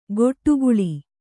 ♪ goṭṭuguḷi